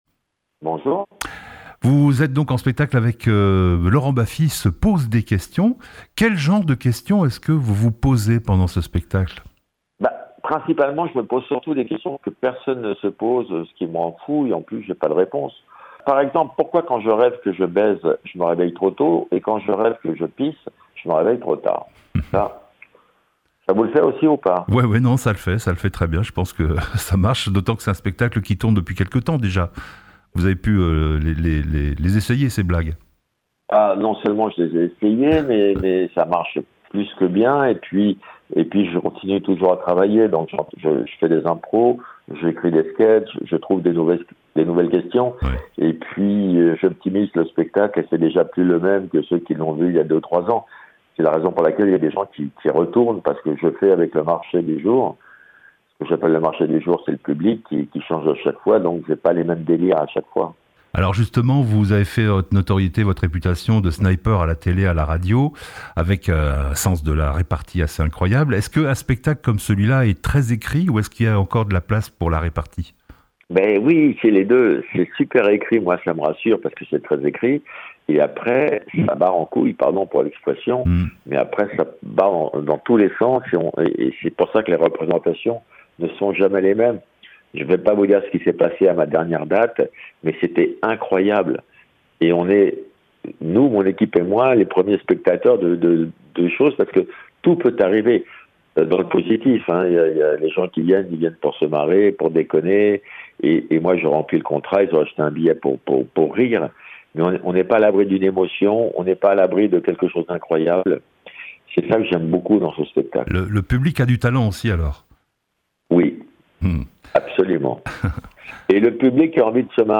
Laurent Baffie en interview sur RDL !